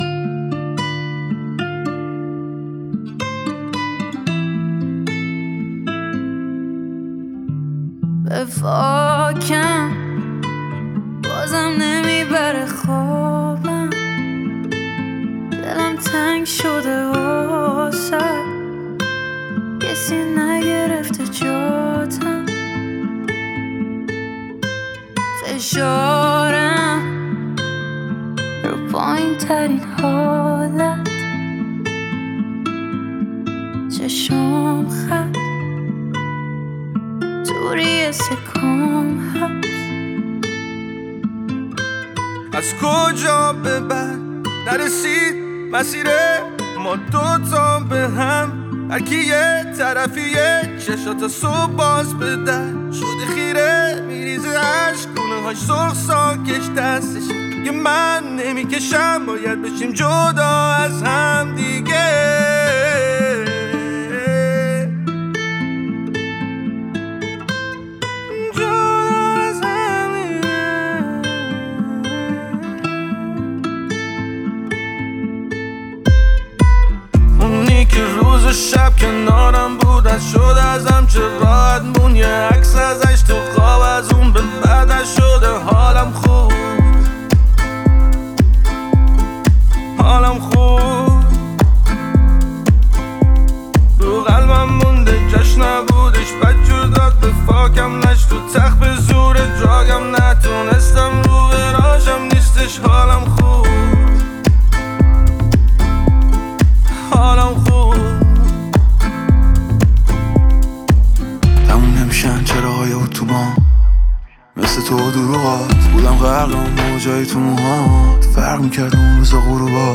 Iranian music
Genres: Persian Pop, R&B, Hip-Hop